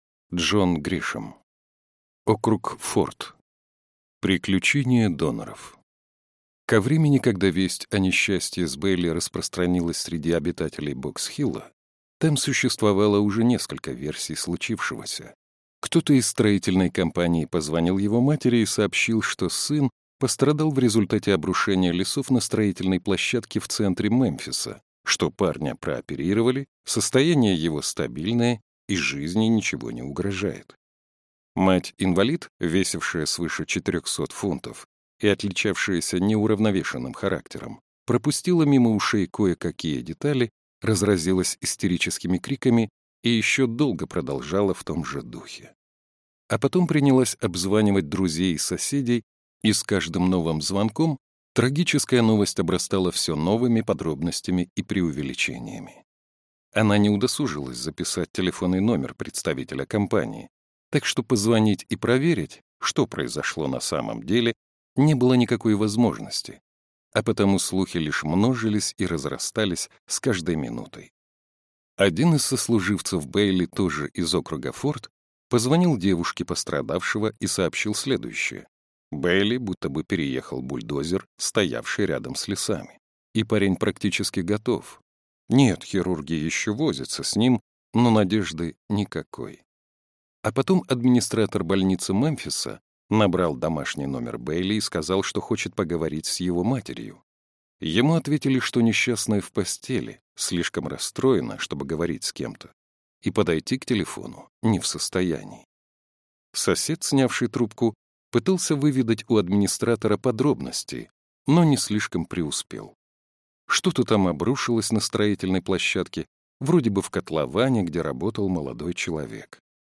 Аудиокнига Округ Форд (сборник) | Библиотека аудиокниг